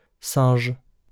wymowa:
IPA/sɛ̃ʒ/ ?/i